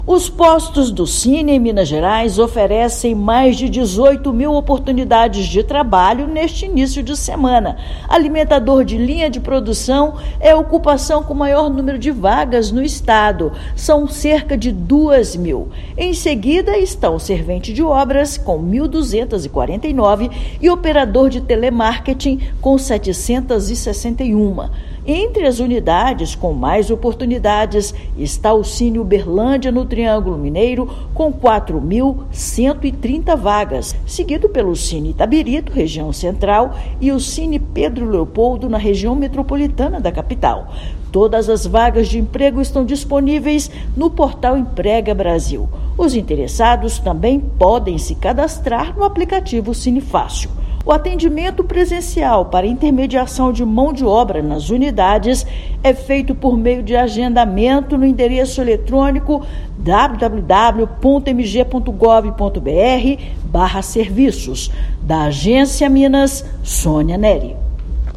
Ocupações com maior número de oportunidades são alimentador de linha de produção, servente de obras e operador de telemarketing. Ouça matéria de rádio.